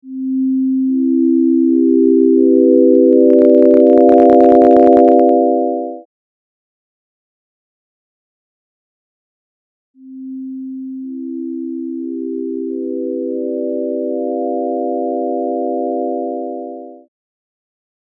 this example and as the first chord builds up, you can hear that it starts to crackle. After a pause, you’ll hear a second chord, with no crackling – the only difference is that the preamp has been turned down.
PreampExample.mp3